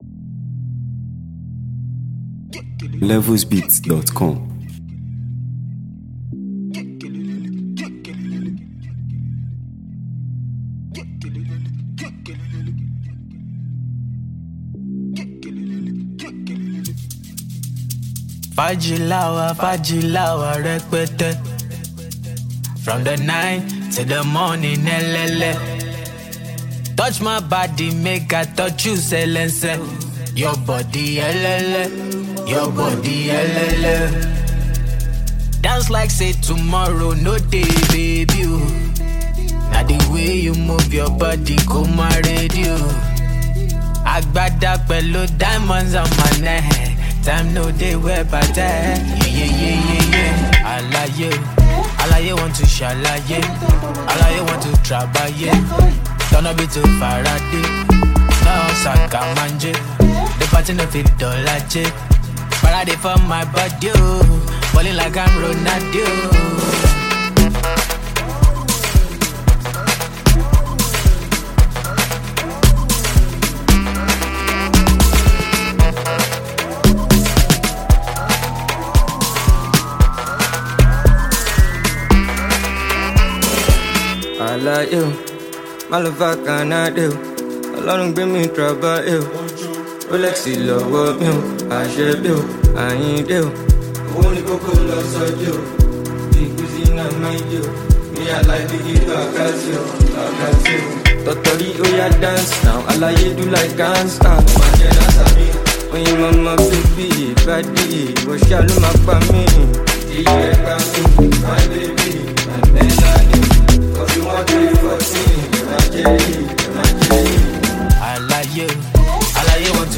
energetic new track
Afrobeats